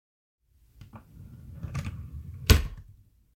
抽屉关闭
描述：木制梳妆台抽屉与金属手柄关闭。